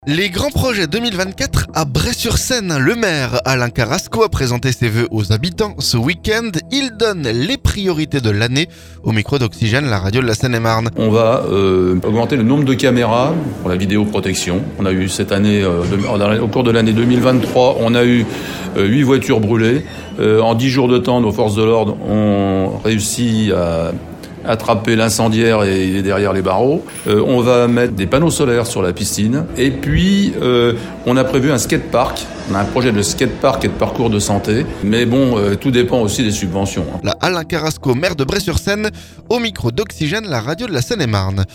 Le maire Alain Carrasco a présenté ses vœux aux habitants ce week-end. Il donne les priorités de l'année au micro Oxygène, la radio de la Seine-et-Marne.